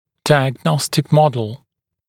[ˌdaɪəg’nɔstɪk ‘mɔdl][ˌдайэг’ностик ‘модл]диагностическая модель